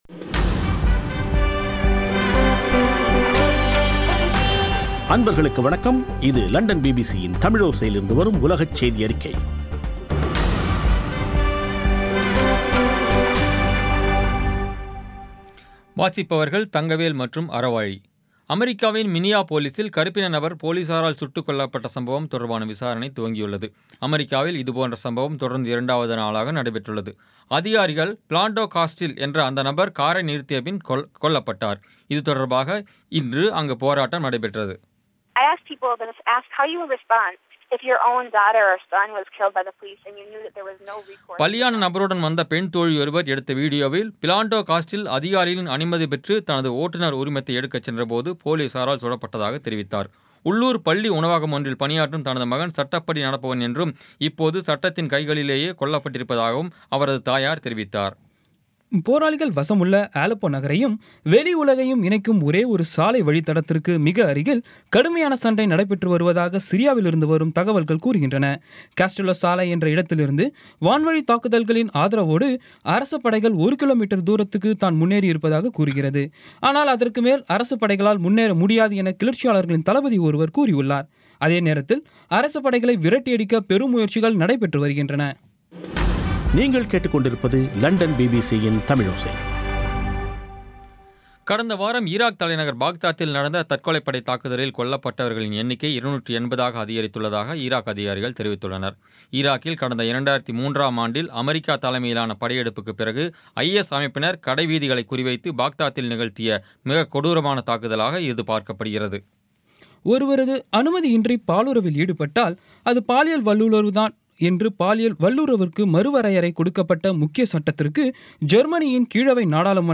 இன்றைய (ஜூலை 7ம் தேதி ) பிபிசி தமிழோசை செய்தியறிக்கை